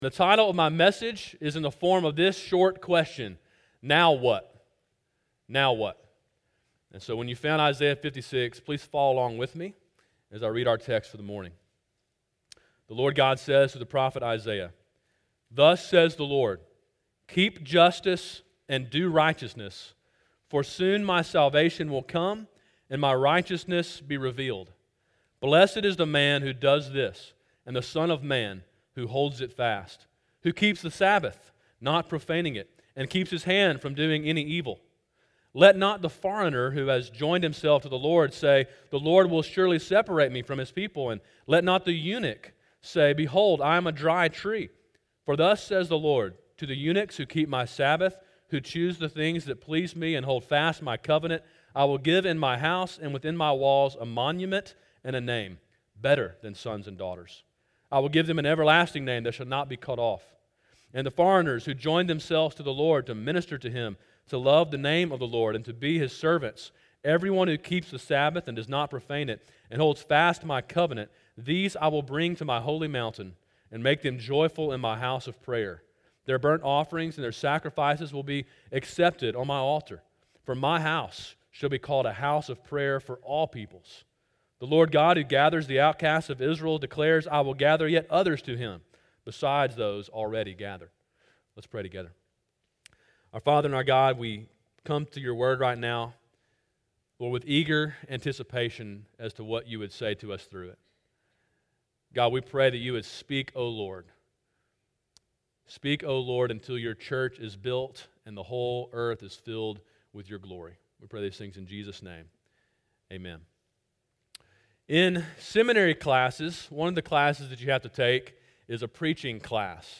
Sermon: “Now What?” (Isaiah 56:1-8) – Calvary Baptist Church